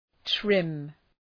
Προφορά
{trım}